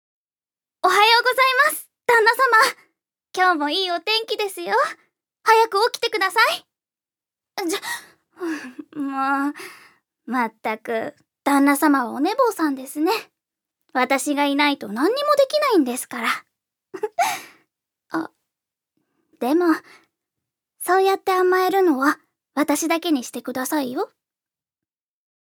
預かり：女性
セリフ１